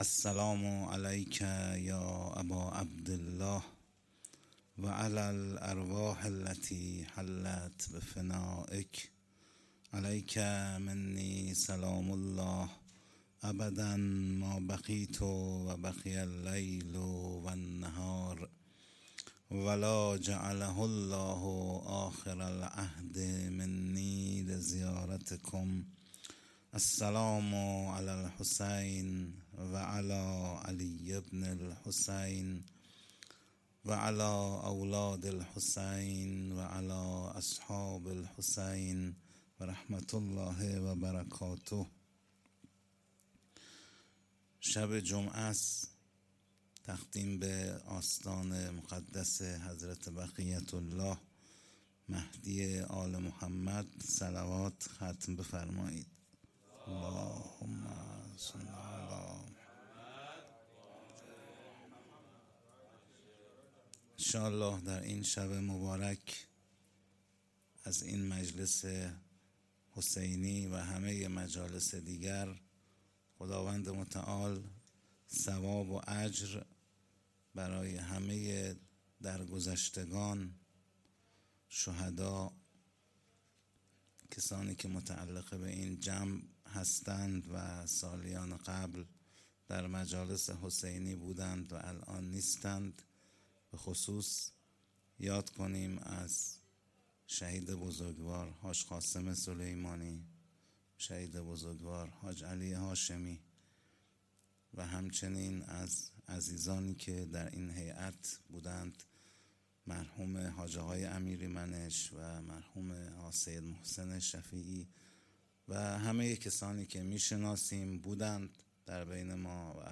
0 0 سخنرانی
مراسم شب سوم شهادت امام سجاد علیه السلام